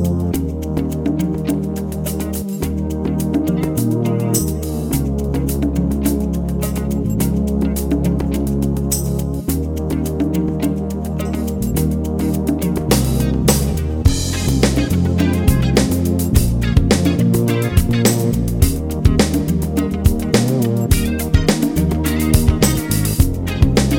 No Verse Harmonies Soul / Motown 4:28 Buy £1.50